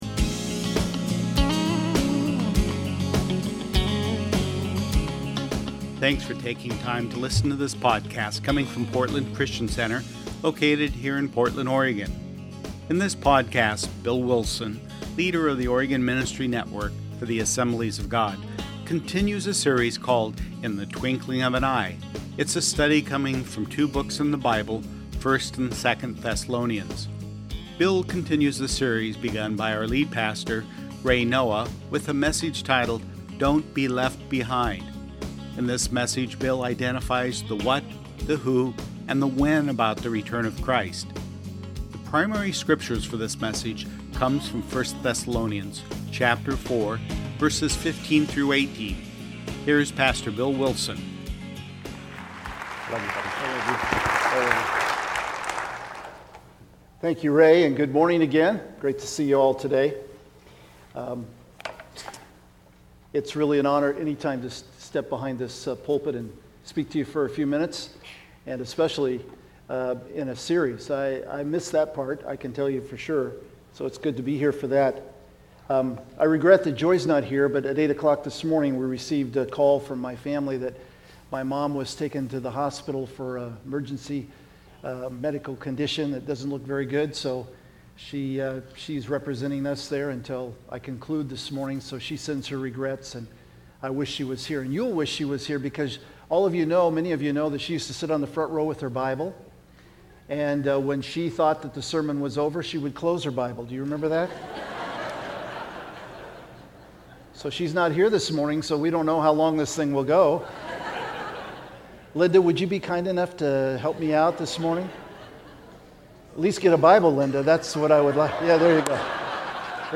Sunday Messages